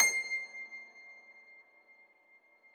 53k-pno22-C5.aif